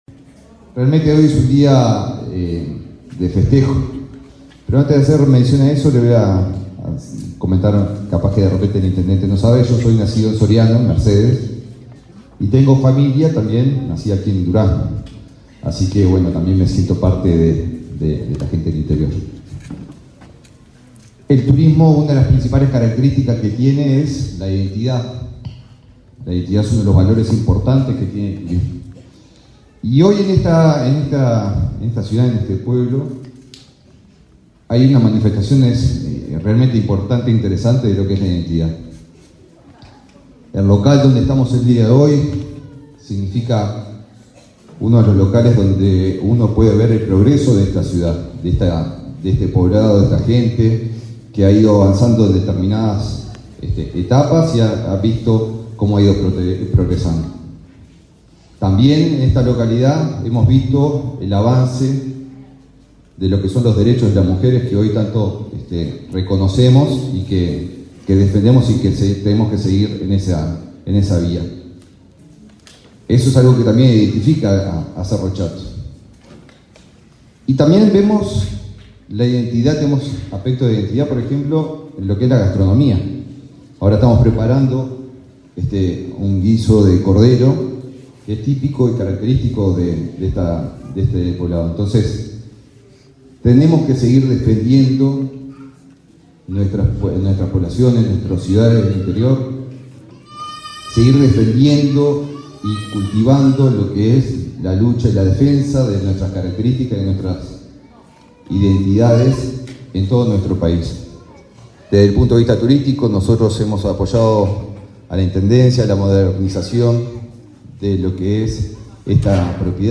Palabras de autoridades en celebración en Cerro Chato
El ministro de Turismo, Eduardo Sanguinetti, y la vicepresidenta de la República, Beatriz Argimón, participaron, en la localidad de Cerro Chato, cuya